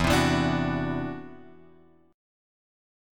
E7sus2#5 chord {0 3 2 x 3 2} chord